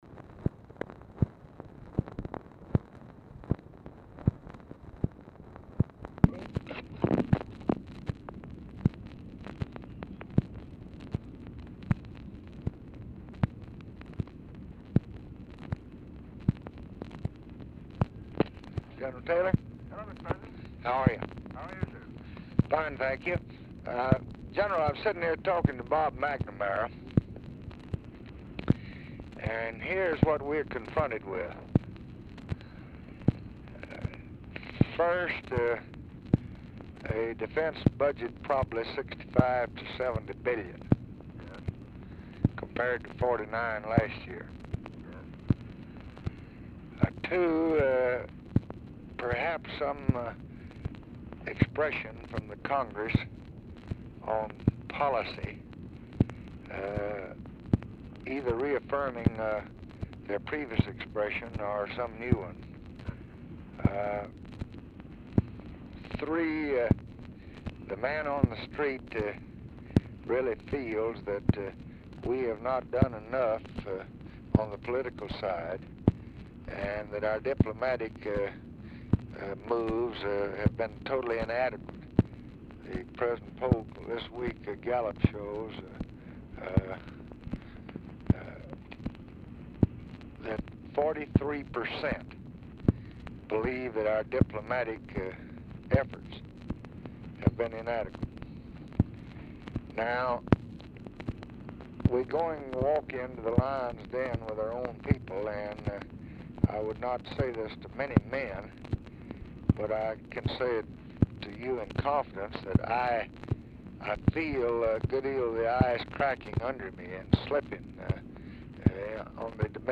Telephone conversation # 9339, sound recording, LBJ and MAXWELL TAYLOR, 12/27/1965, 8:56PM | Discover LBJ
Format Dictation belt
Location Of Speaker 1 LBJ Ranch, near Stonewall, Texas